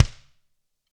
Index of /90_sSampleCDs/ILIO - Double Platinum Drums 1/CD4/Partition A/GRETSCHKICKD